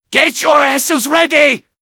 medic_mvm_ask_ready01.mp3